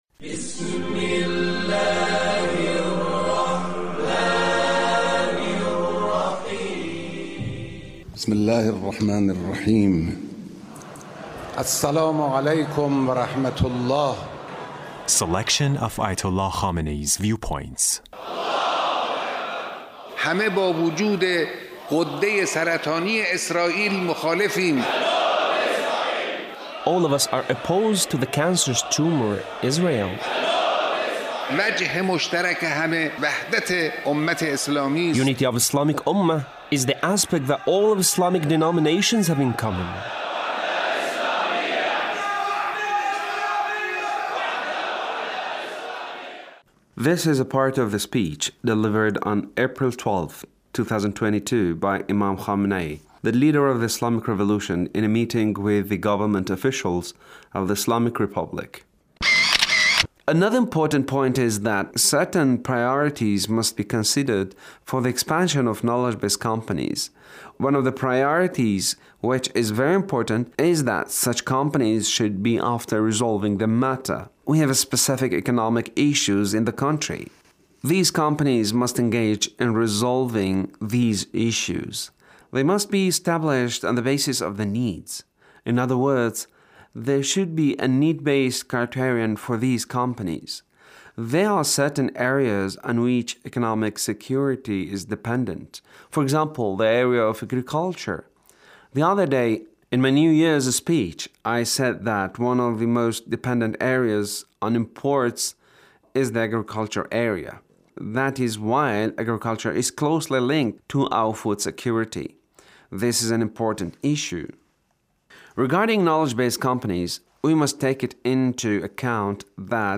Leader's speech (1393)
The Leader's speech in a meeting with Government Officials